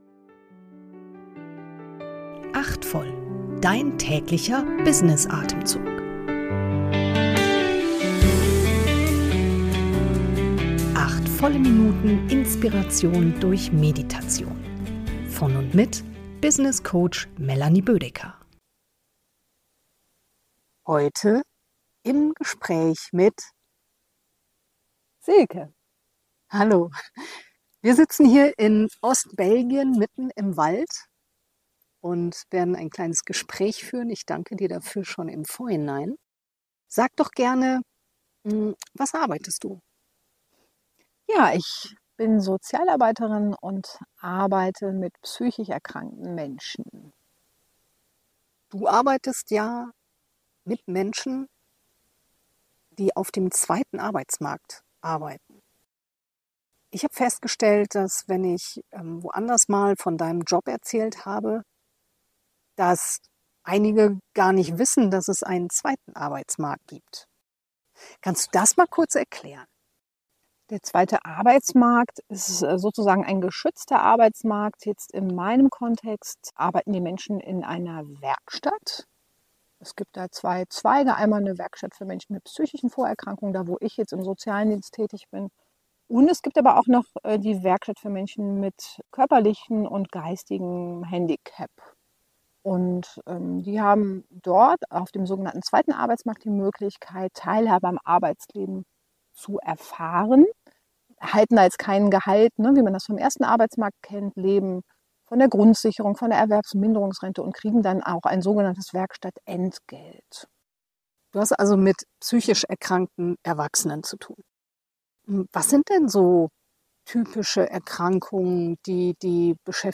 Sozialarbeiterin